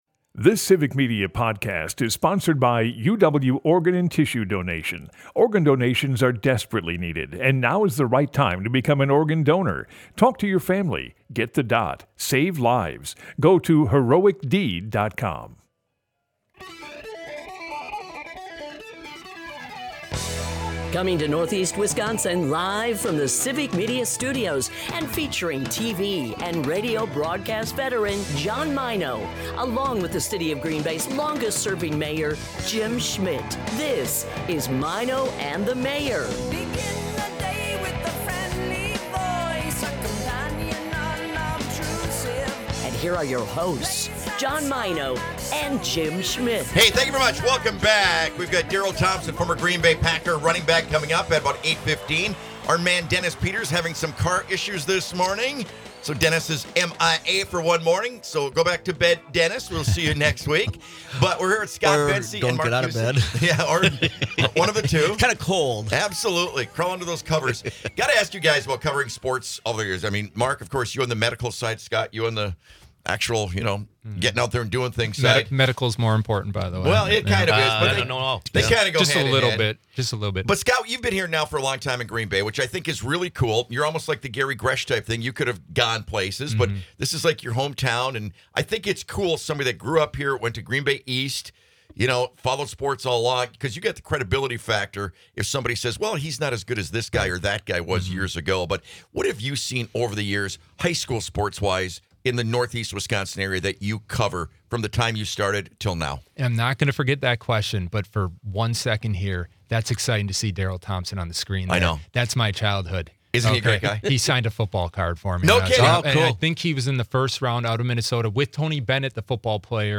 Experience the chemistry and humor between two great friends. Broadcasts live 6 - 9am in Oshkosh, Appleton, Green Bay and surrounding areas.